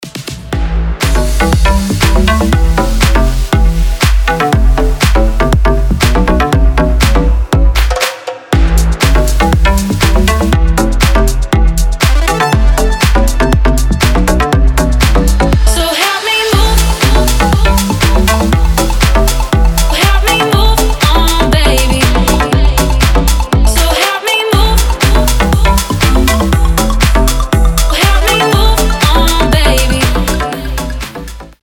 Клубные рингтоны Добавлен